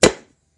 人类的声音 " 纸箱处理
标签： 噪音 处理 纸板
声道立体声